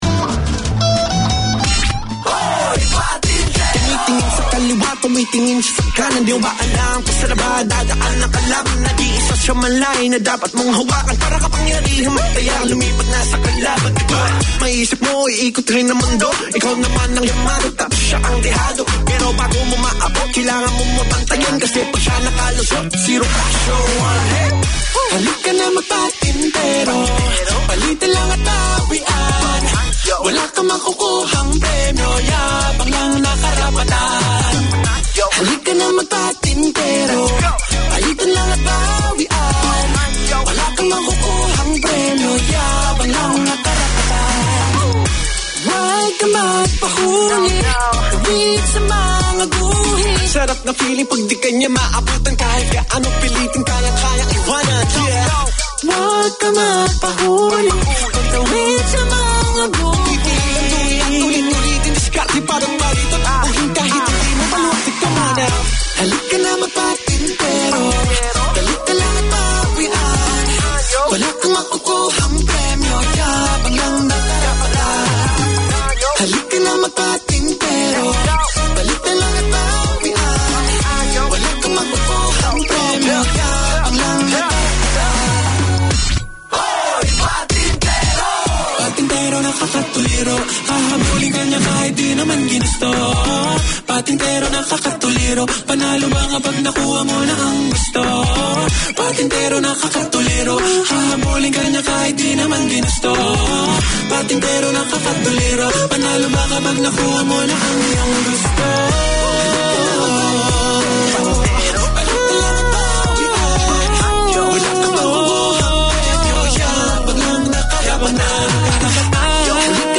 Community Access Radio in your language - available for download five minutes after broadcast.
The Filipino Show No shows scheduled this week Community magazine Language